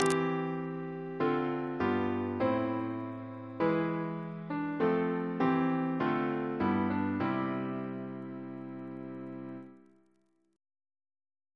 Single chant in C Composer: Henry W. Baker (1835-1910) Reference psalters: ACB: 9; PP/SNCB: 34; RSCM: 166